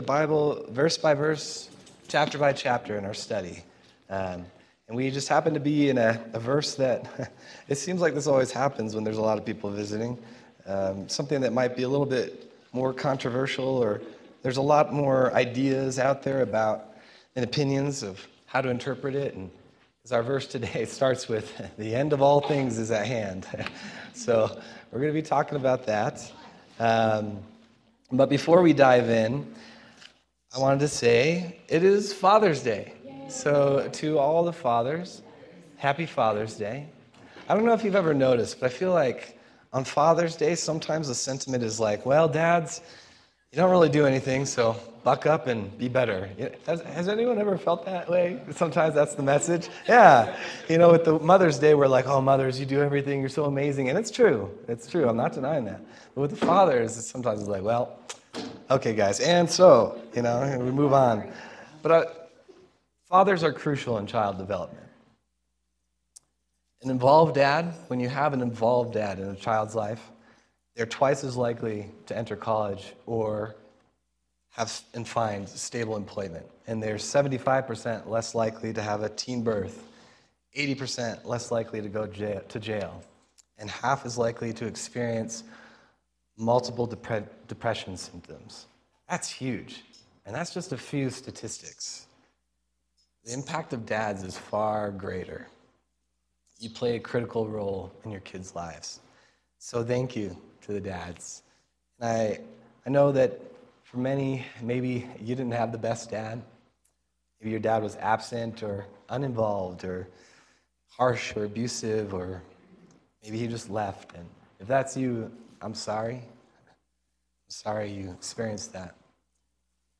June 15th, 2025 Sermon